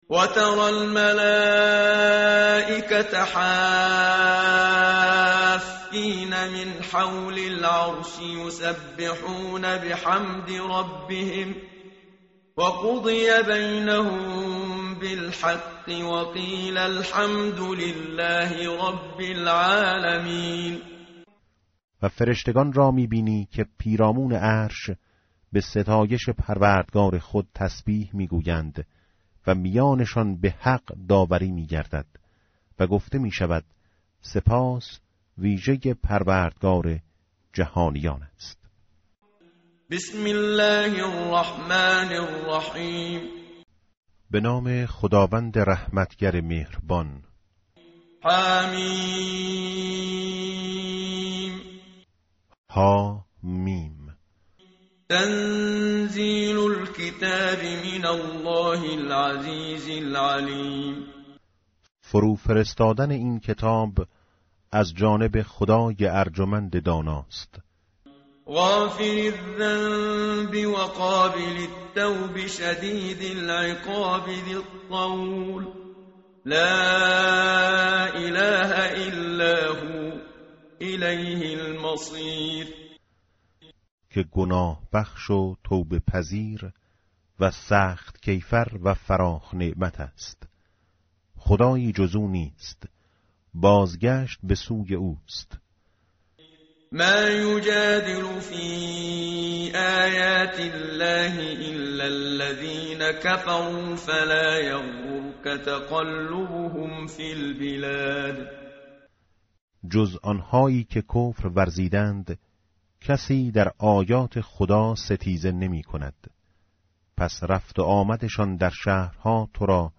متن قرآن همراه باتلاوت قرآن و ترجمه
tartil_menshavi va tarjome_Page_467.mp3